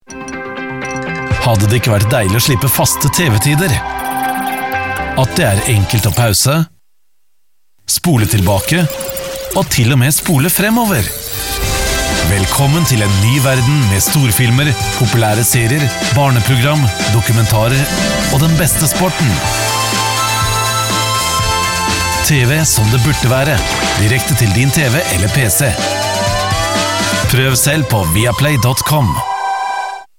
movie deep male experience voice commercial tv radio studio language norwegish norwegian trustworthy
Sprechprobe: Sonstiges (Muttersprache):